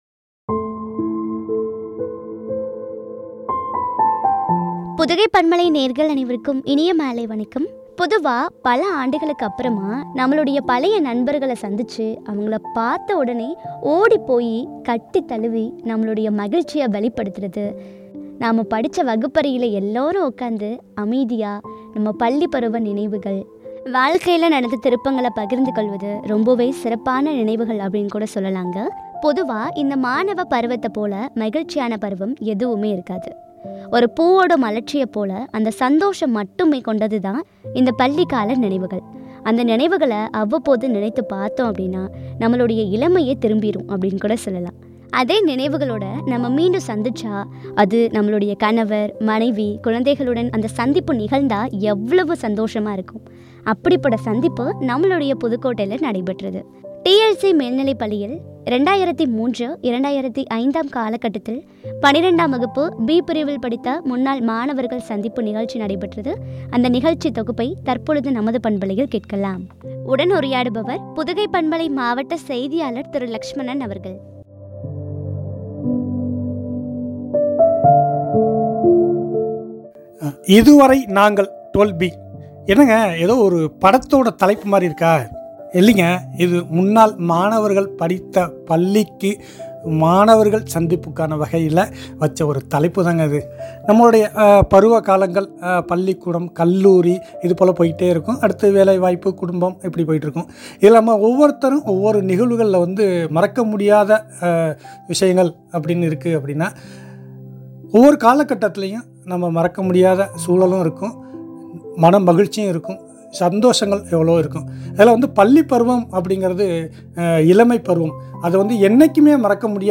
புதுக்கோட்டை , டி இ எல் சி பள்ளியில் “- 12B” 2003- 2005 , முன்னாள் மாணவர்கள் சந்திப்பு , “அனுபவப் பகிர்வு” நிகழ்ச்சி தொகுப்பு குறித்து வழங்கிய உரையாடல்.